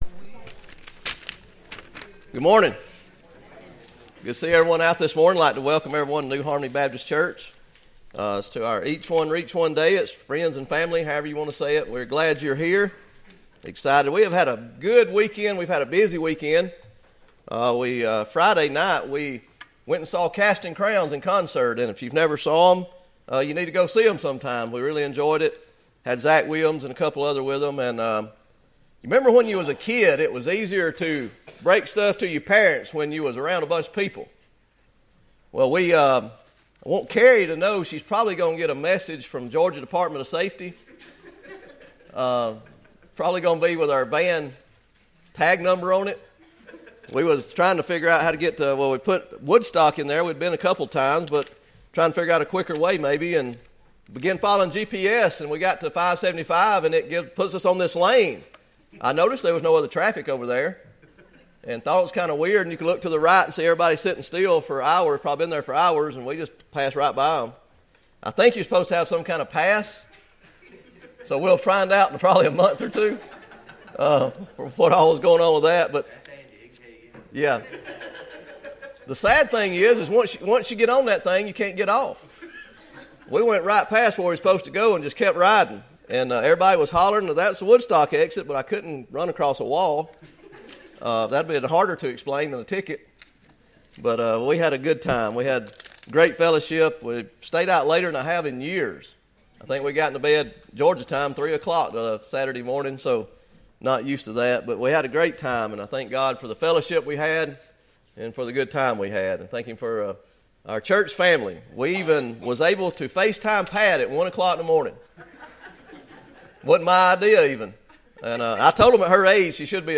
Romans 3:23 Service Type: Sunday Morning Bible Text